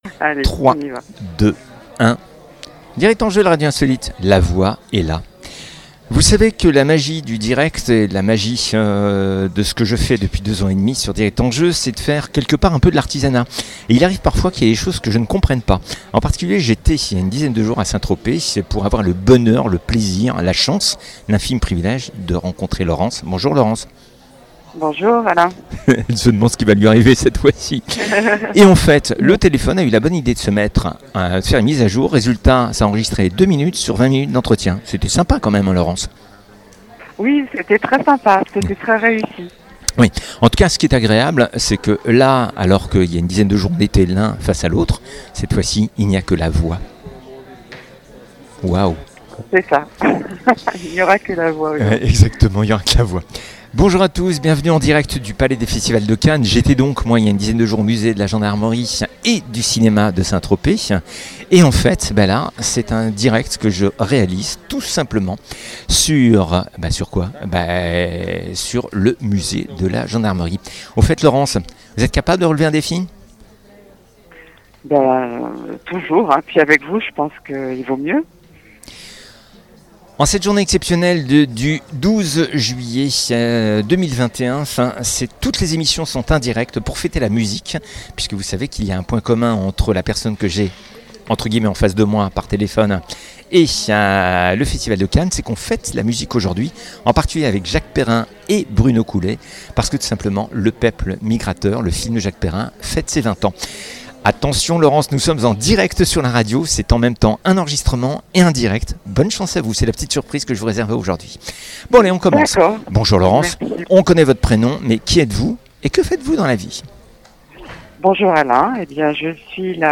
direct du Festival de Cannes